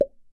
流行的声音效果
描述：嘴巴流行的声音。
标签： 弹出 气球 气泡 气泡 弹出
声道立体声